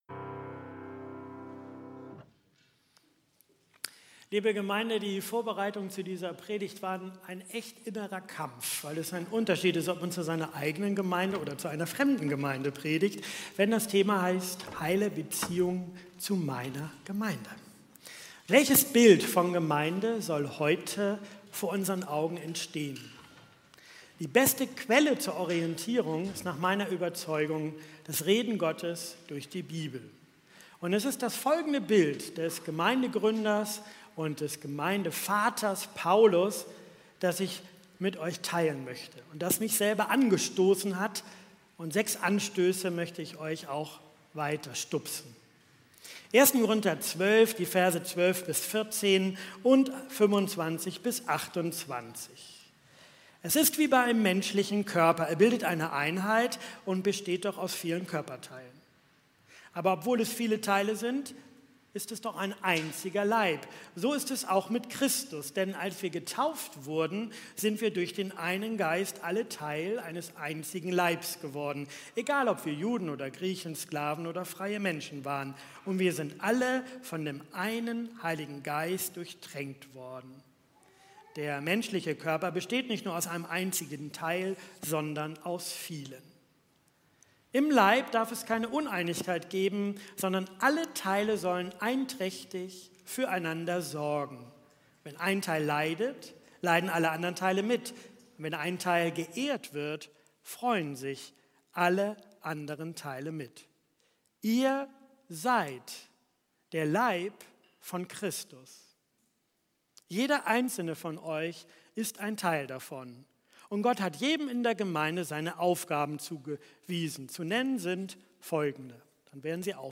Von dieser Predigt wird auch das Manuskript des Predigers als pdf zur Verfügung gestellt.